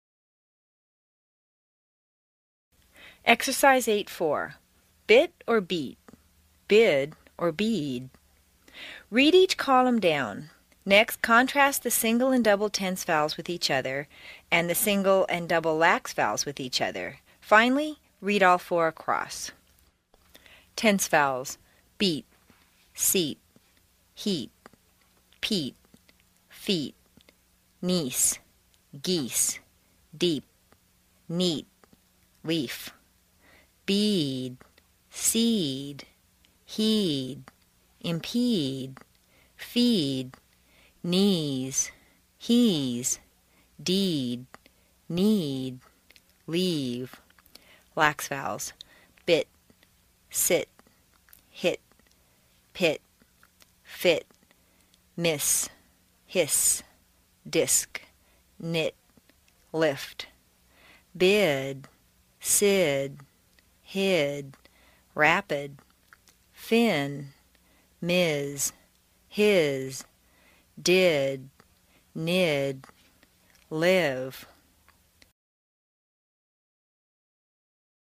在线英语听力室美式英语正音训练第93期:第8章 练习4的听力文件下载,详细解析美式语音语调，讲解美式发音的阶梯性语调训练方法，全方位了解美式发音的技巧与方法，练就一口纯正的美式发音！